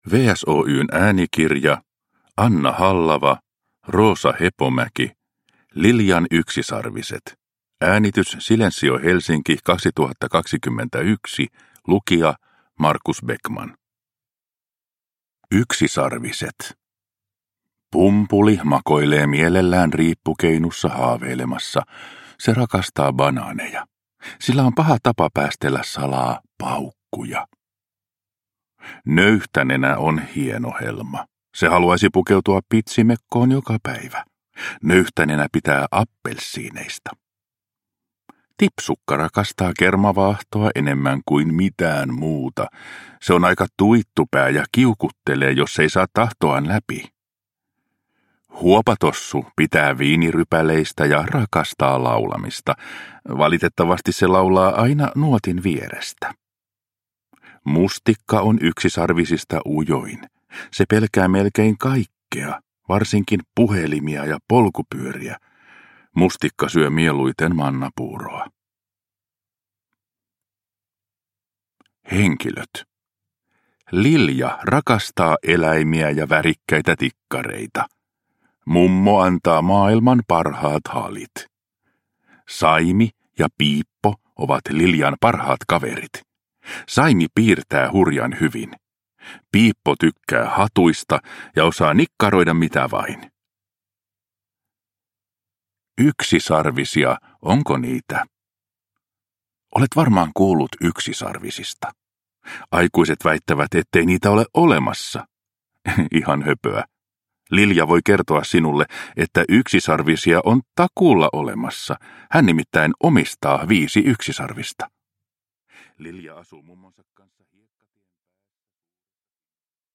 Liljan yksisarviset – Ljudbok – Laddas ner